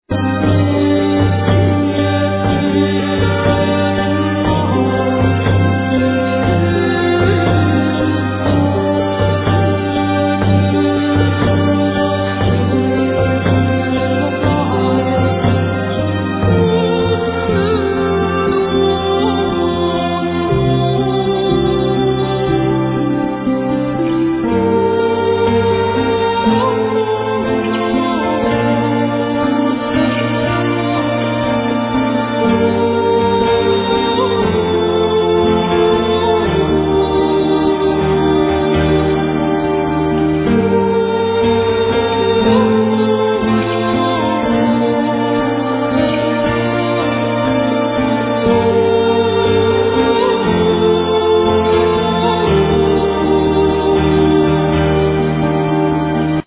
Voice, Percussions, Flute
Guitars
Bass, Drums, Voice
Whispers, Chimes, Bells